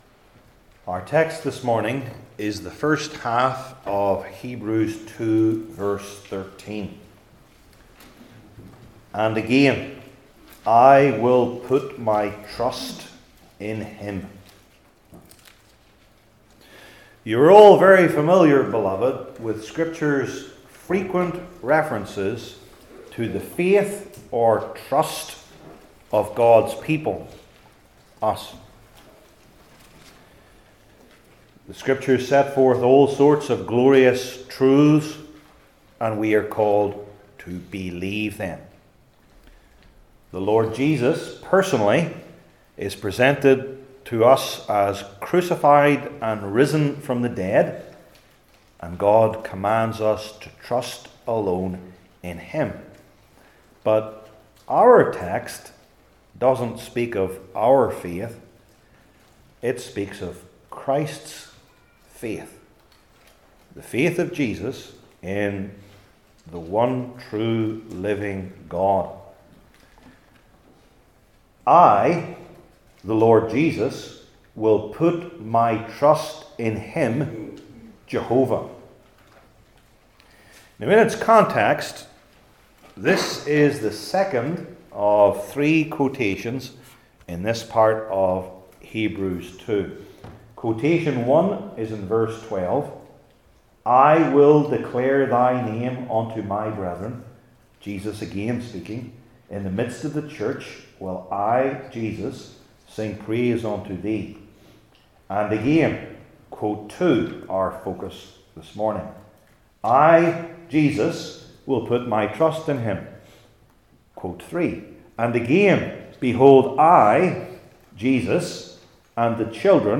New Testament Sermon Series I. The Source of the Quotation II.